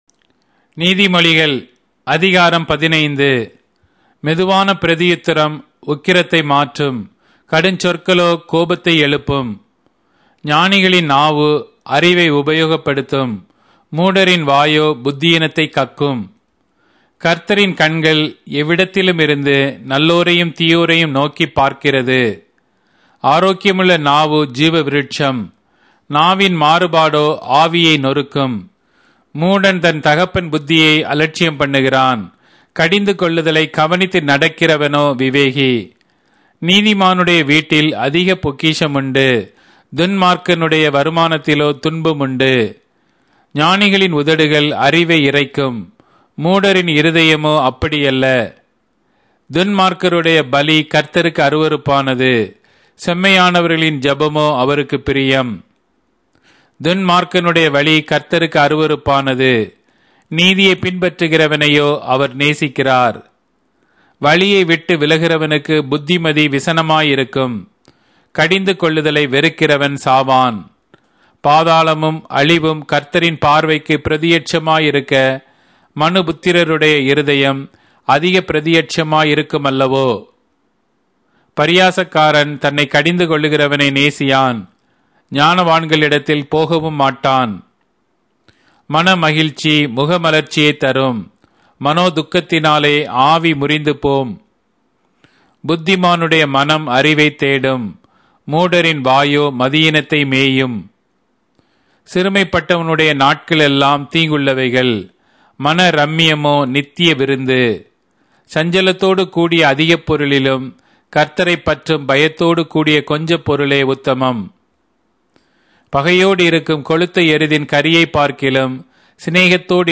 Tamil Audio Bible - Proverbs 1 in Hov bible version